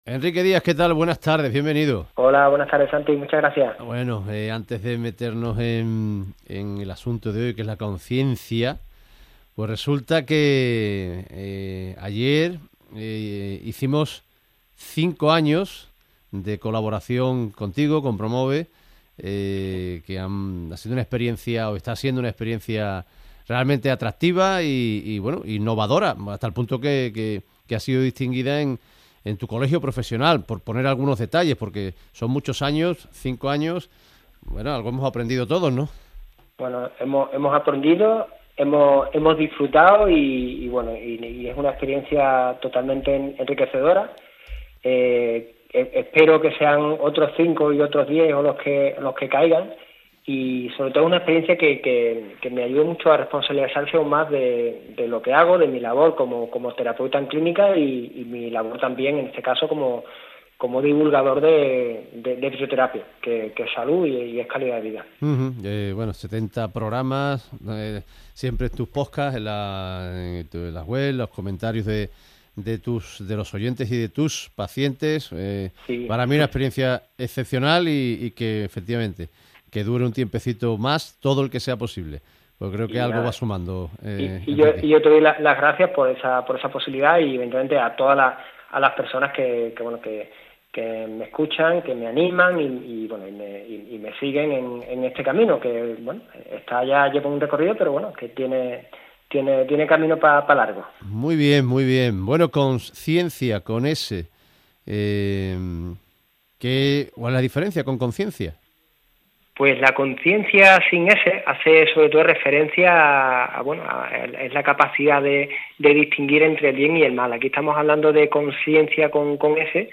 Programa de radio en la cadena SER En la matinal de los domingos en Ser Deportivos Andalucía Si quieres escuchar este programa puedes hacerlo en la barra de sonido.